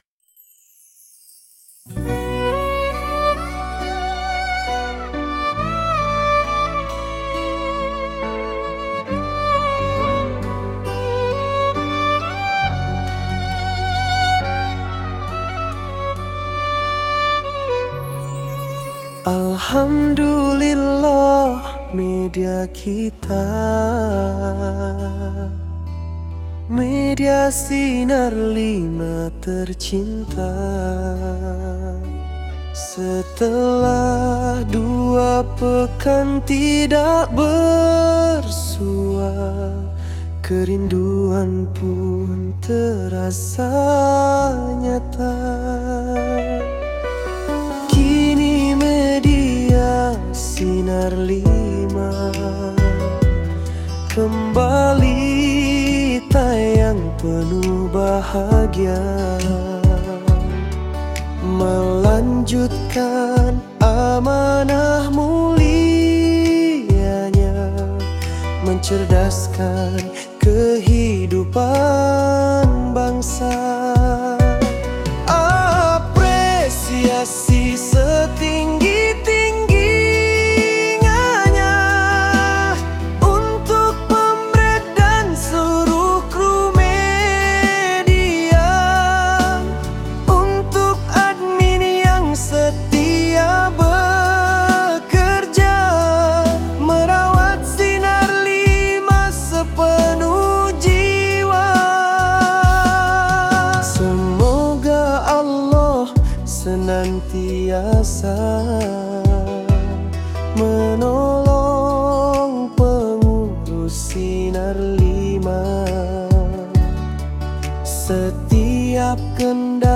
Dengarkan lagunya yang syahdu dan menyentuh syair tersebut!
Dangdut.mp3